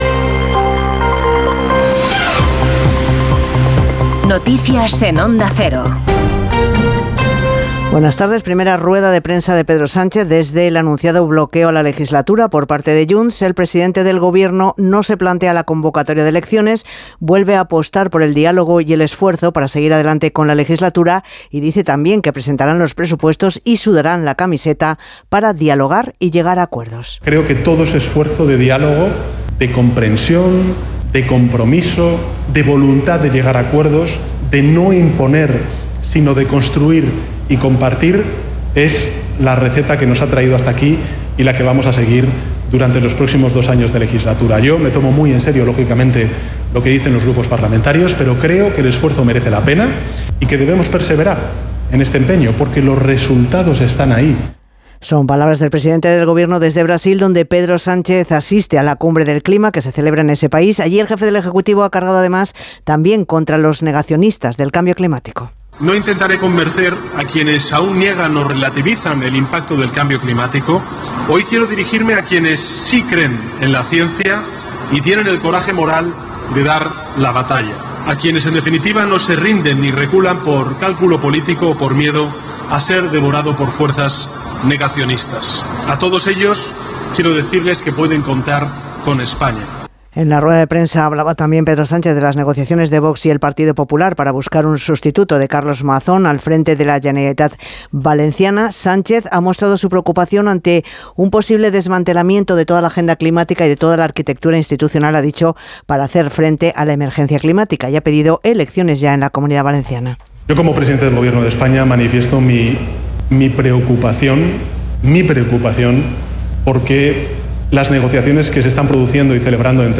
Conoce la ultima hora y toda la actualidad del dia en los boletines informativos de Onda Cero. Escucha hora a hora las noticias de hoy en Espana y el mundo y mantente al dia con la informacion deportiva.